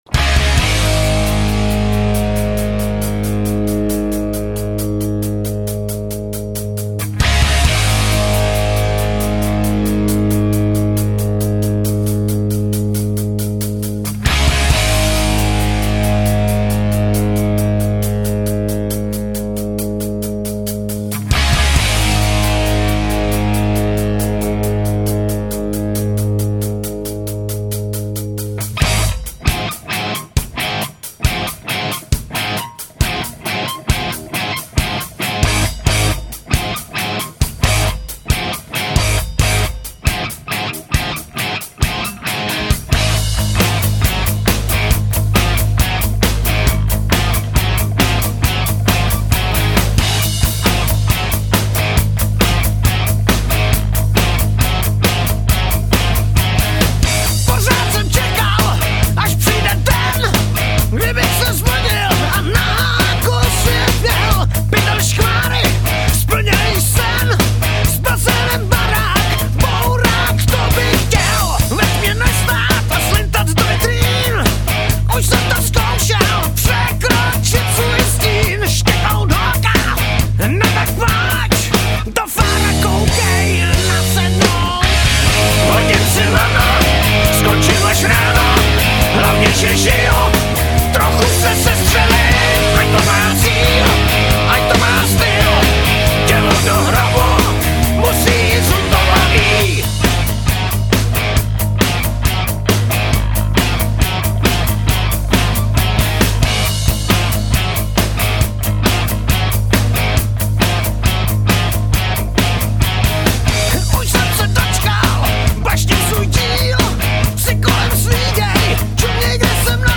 kytary
basovß kytara